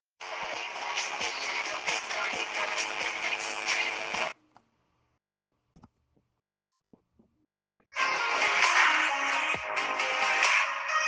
Verzerrter Ton mit Logitech G733 nach ca 10min
Ich hab mal eine Aufnahme davon angehängt ist leider etwas übersteuert.